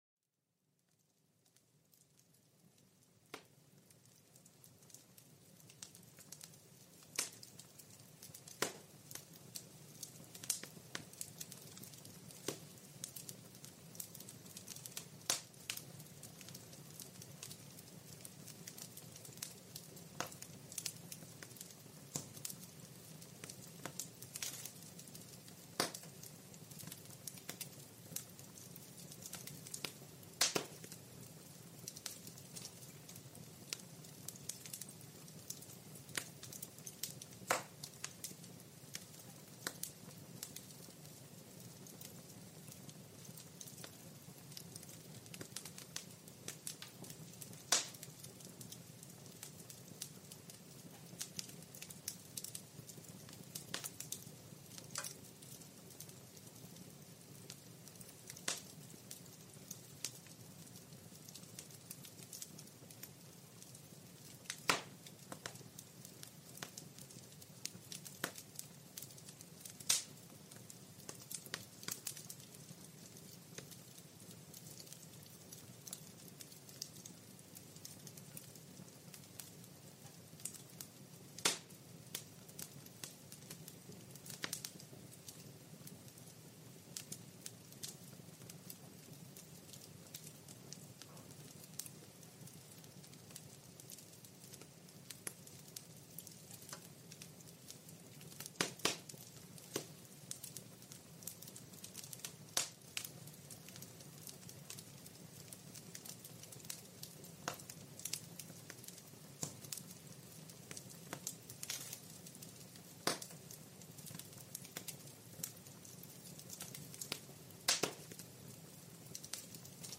Découvrez le crépitement réconfortant d'un feu de cheminée dans cet épisode. Laissez-vous envelopper par la chaleur douce et les étincelles hypnotiques.